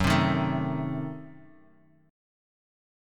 F#dim7 chord